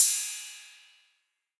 808CY_6_Orig_ST.wav